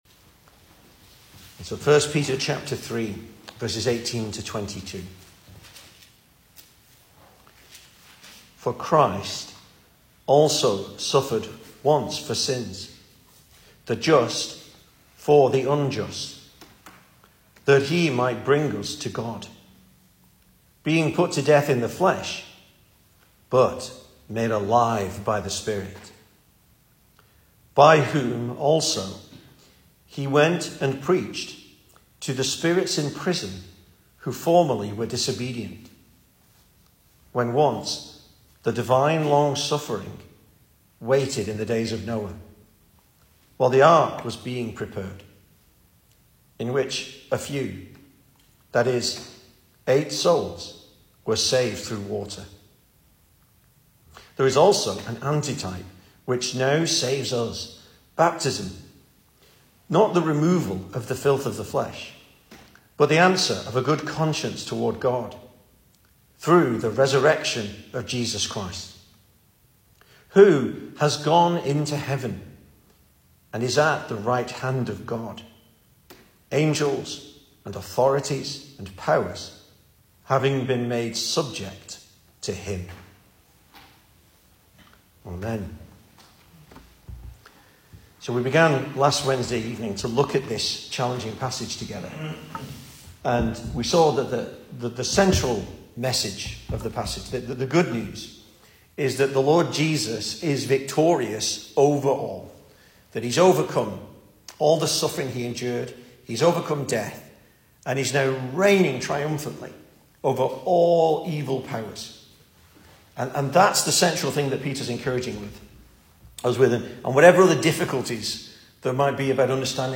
2023 Service Type: Weekday Evening Speaker